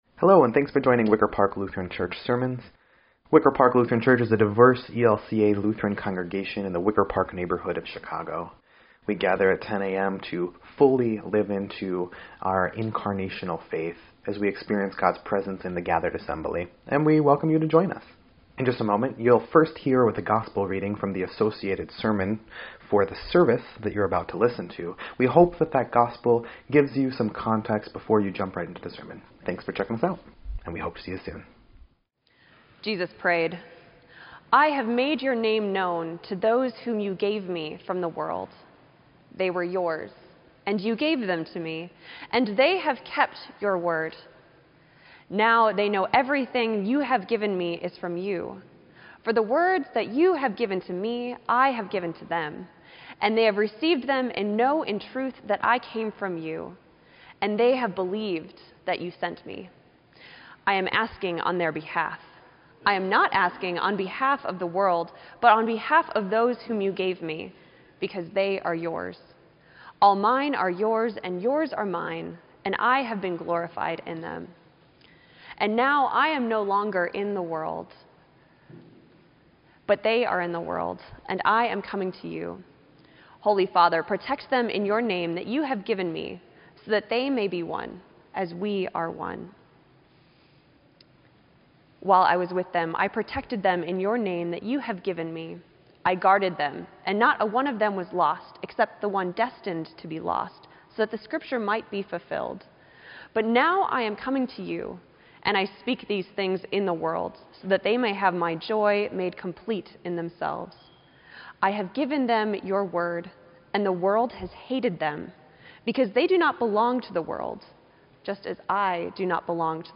Sermon_5_13_18_EDIT.mp3